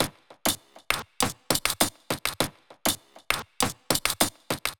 TT_loop_brazilian_wax_100.wav